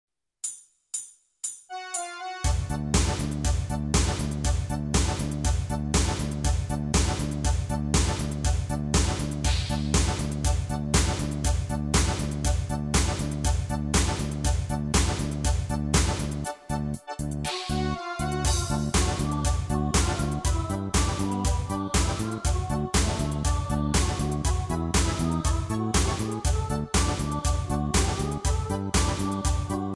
Tempo: 120 BPM.
MP3 with melody DEMO 30s (0.5 MB)zdarma